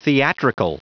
Prononciation du mot theatrical en anglais (fichier audio)
Prononciation du mot : theatrical